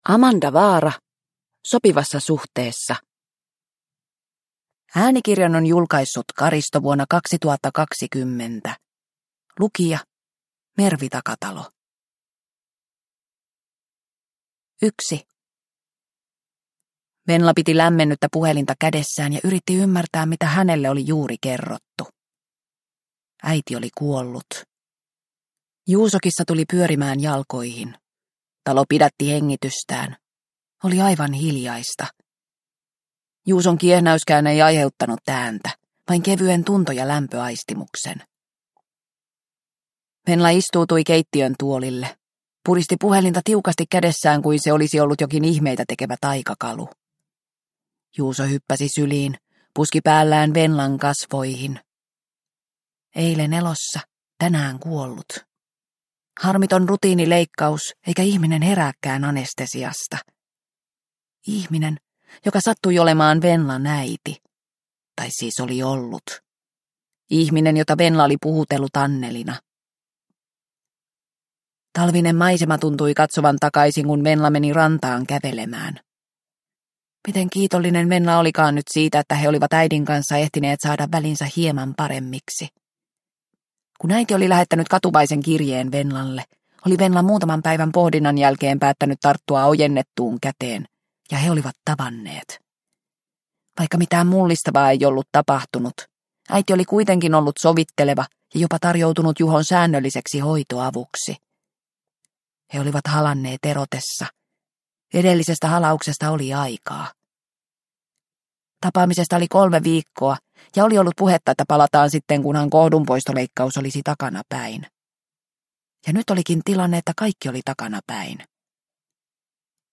Sopivassa suhteessa – Ljudbok – Laddas ner